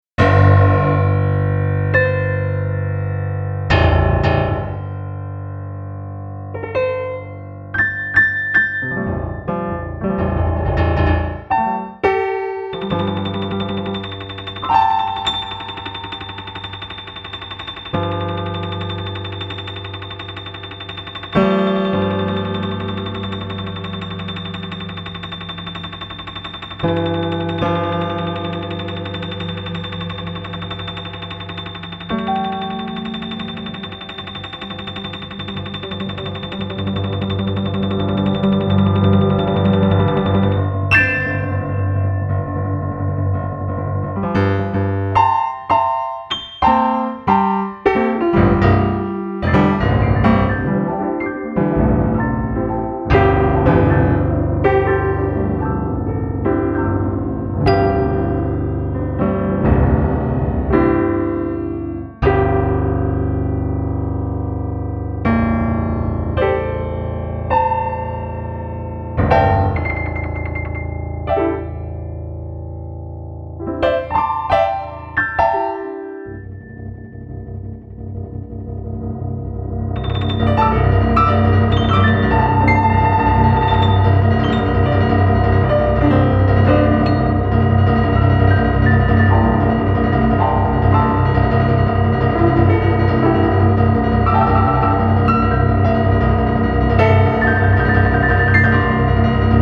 a MIDI composition
for synthesized piano
Mouse clicks, boxing gloves, vibrancy, and mischief.